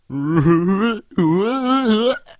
Unlike a normal metal detector though, instead of a simple beep, it plays a wonderful noise, and instead of the frequency of the noises indicating proximity, its volume indicates proximity.
Wonderful_noise.mp3